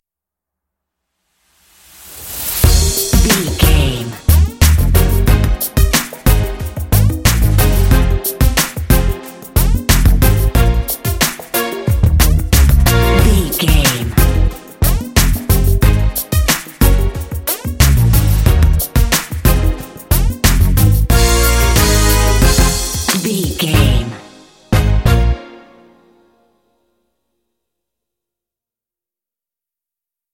Aeolian/Minor
B♭
cool
urban
futuristic
synthesiser
bass guitar
drums
strings
80s
synth-pop